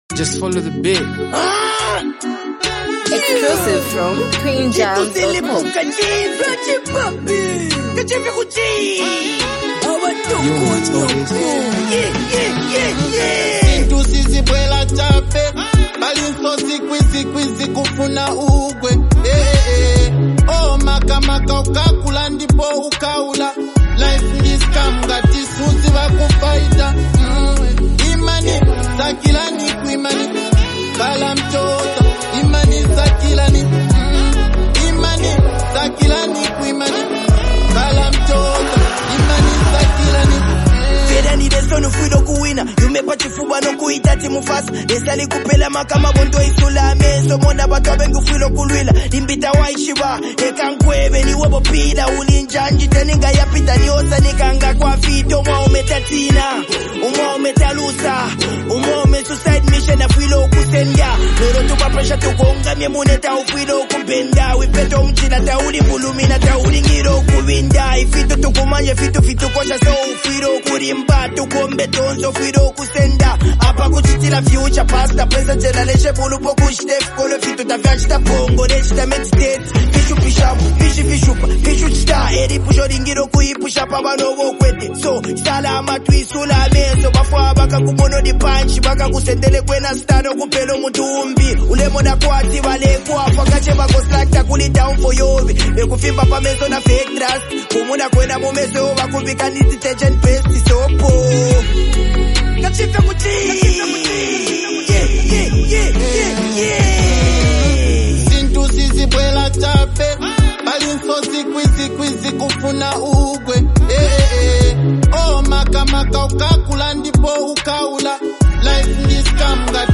an uplifting and motivational song